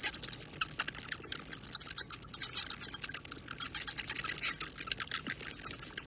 Adding code and sound effects for the motion sensor bat project 2024-10-28 09:33:44 -04:00 270 KiB Raw Permalink History
bat2.wav